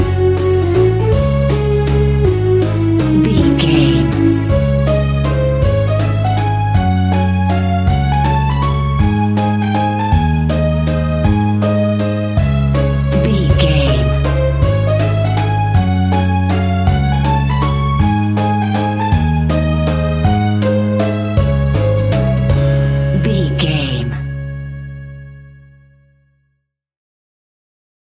Aeolian/Minor
C#
instrumentals
fun
childlike
cute
happy
kids piano